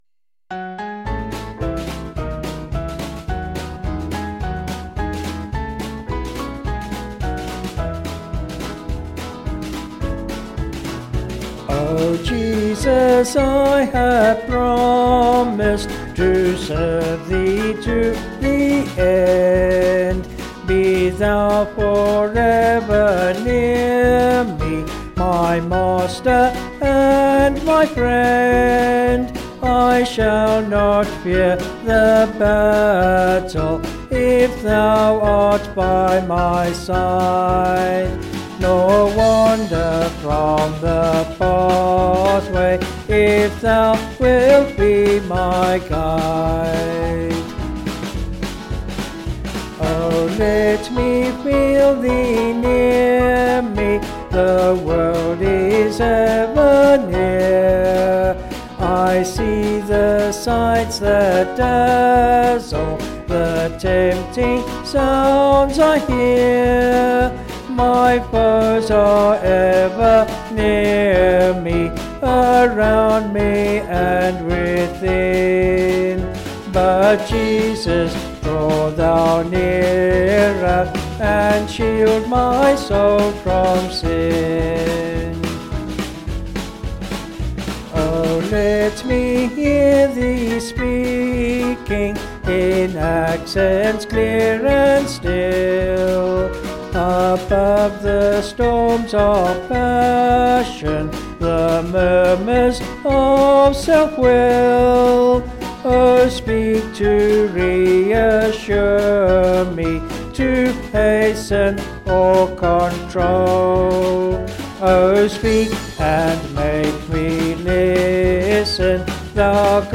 Vocals and Band   265.2kb Sung Lyrics